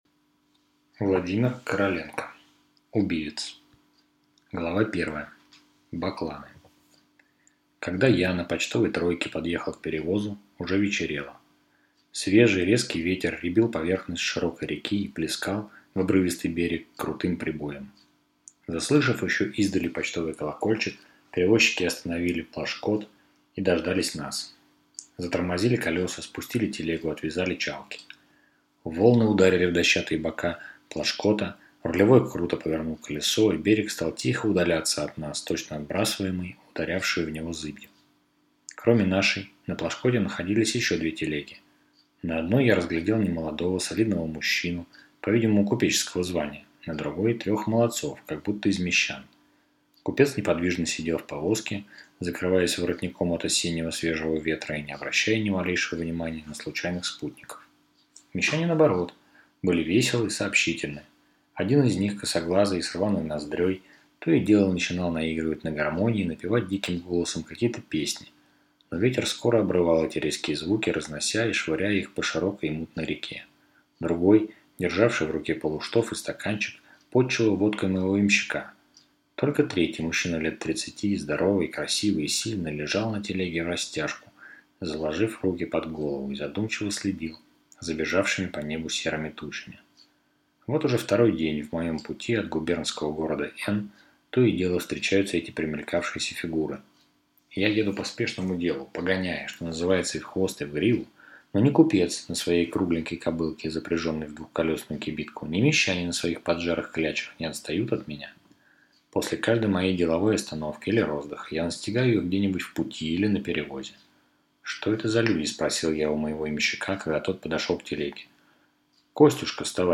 Аудиокнига Убивец | Библиотека аудиокниг